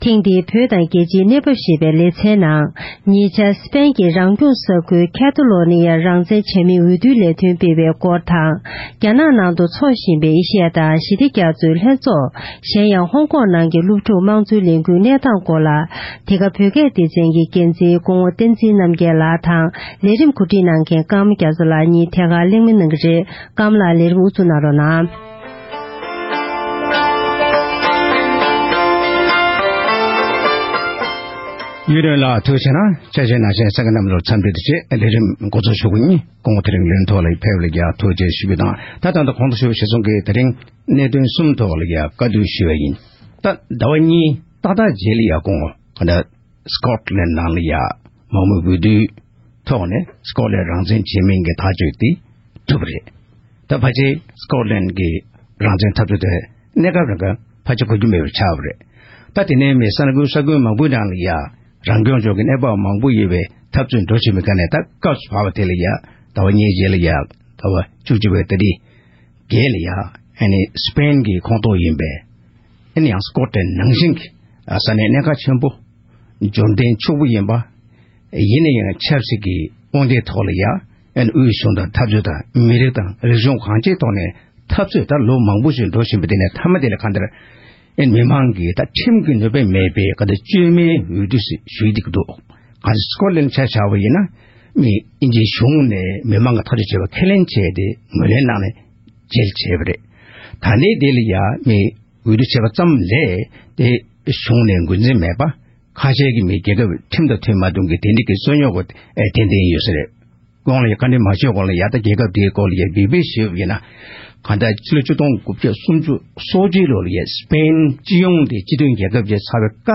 སཔེན་མངའ་ཁོངས་ཀེ་ཊ་ལོ་ནི་ཡ་རང་སྐྱོང་མངའ་སྡེ་རང་བཙན་བྱེད་མིན་གྱི་འོས་བསྡུ་ལས་འགུལ་སོགས་ཀྱི་ཐོག་གླེང་བ།